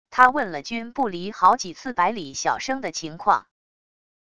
她问了君不离好几次百里晓笙的情况wav音频生成系统WAV Audio Player